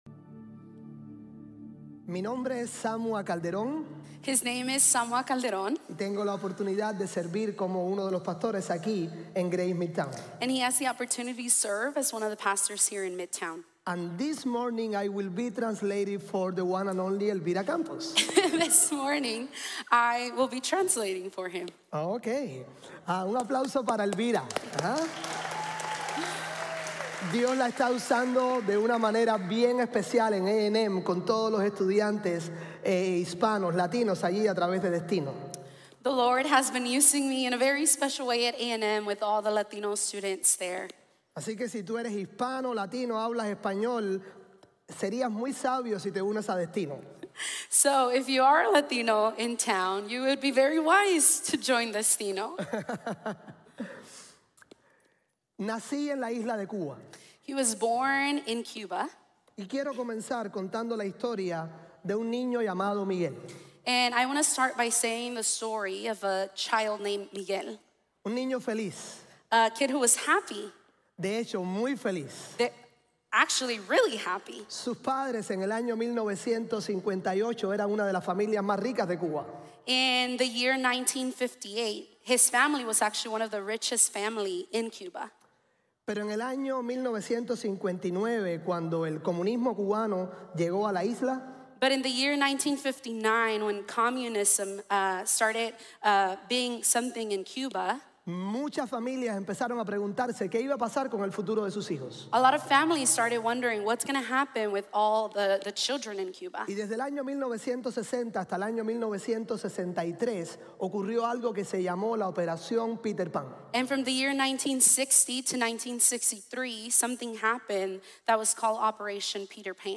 Todos somos Diáconos de Dios | Sermon | Grace Bible Church